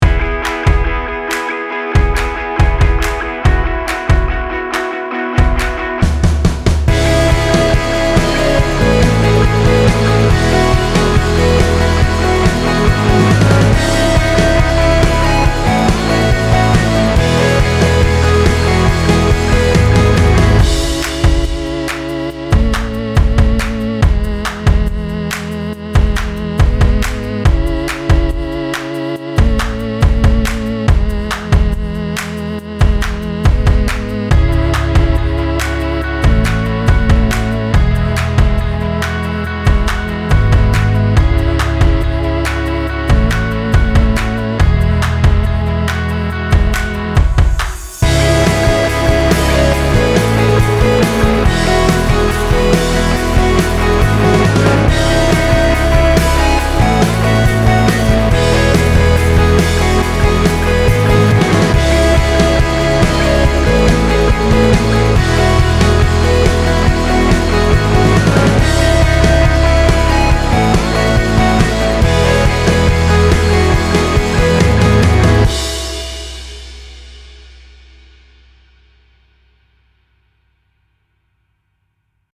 INDIE ROCK - INSTRUMENTALS